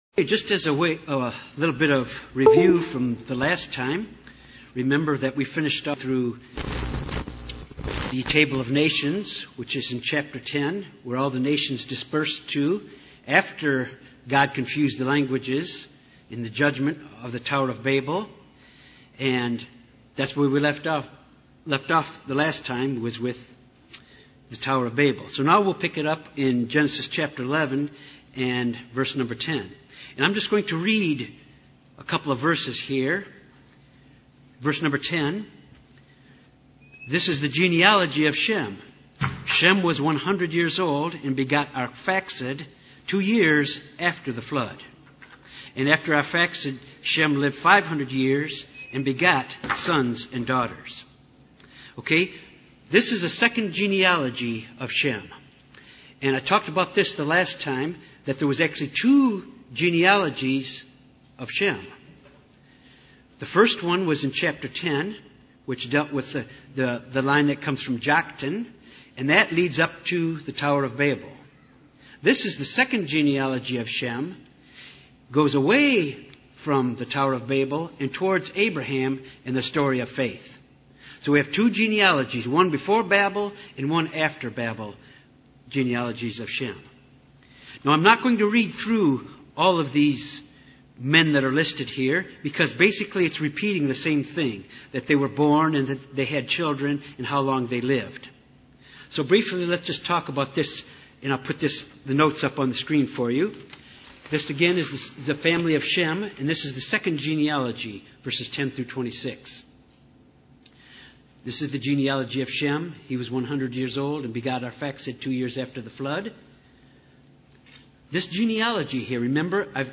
This Bible study focuses on Genesis:11:10 - 13:18.